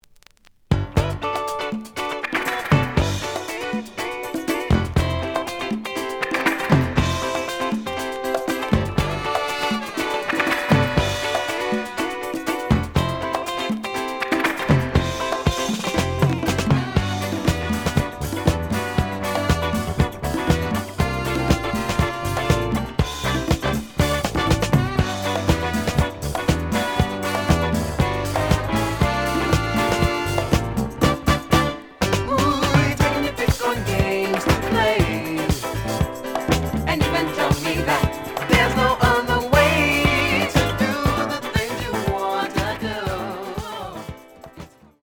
The audio sample is recorded from the actual item.
●Genre: Disco
Some damage on both side labels. Plays good.)